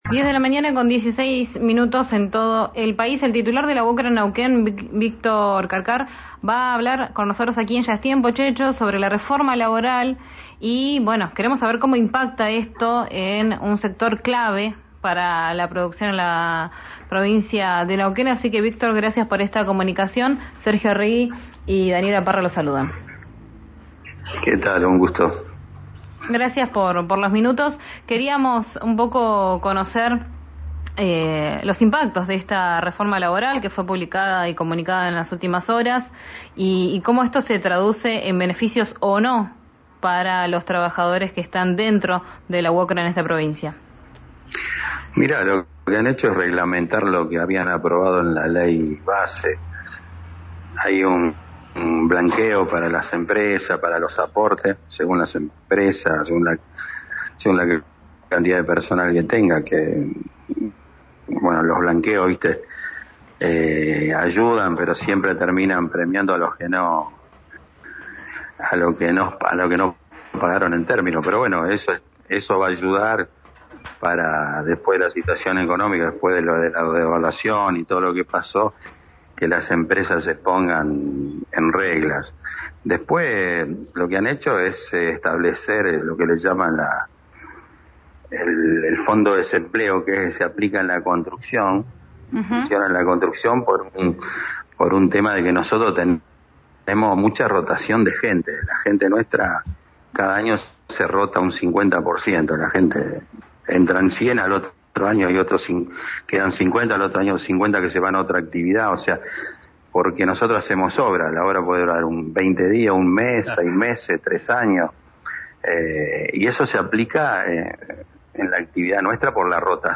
en RÍO NEGRO RADIO: